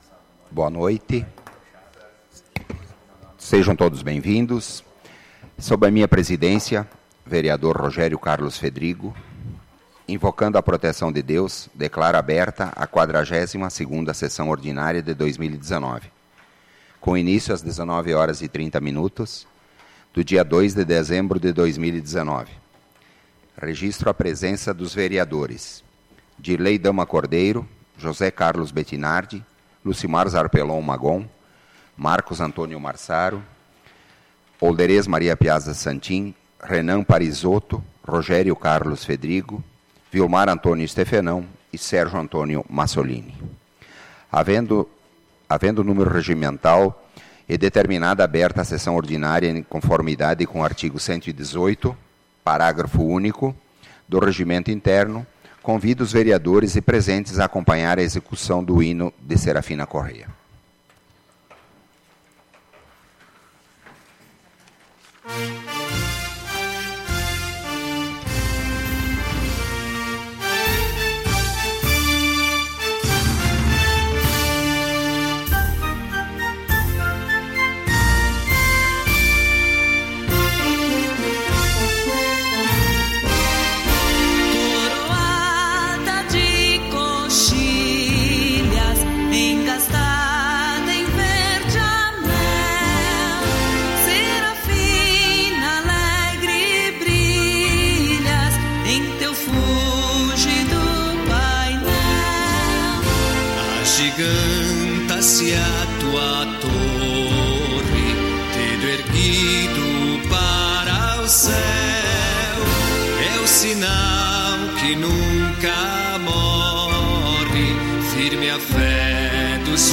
SAPL - Câmara de Vereadores de Serafina Corrêa - RS
Tipo de Sessão: Ordinária